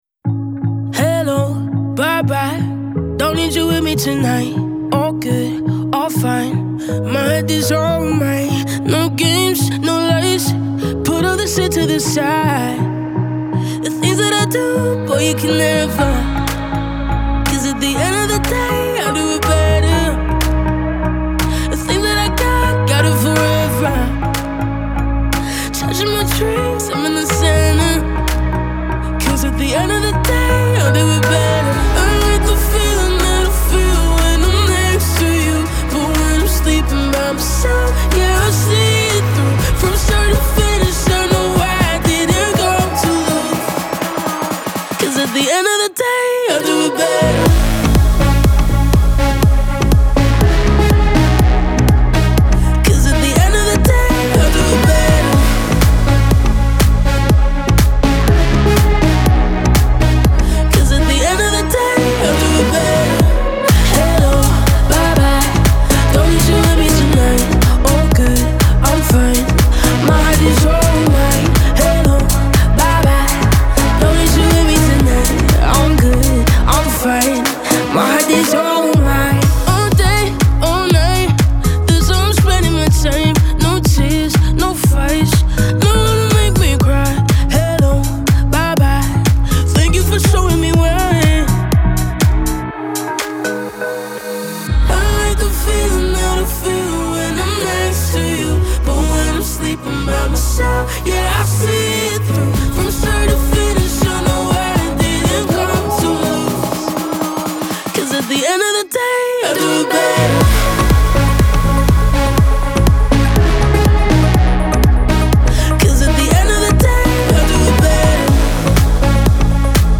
Genre : Electro, Alternative